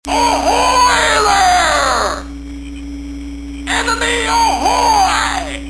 • Voice Tones Ringtones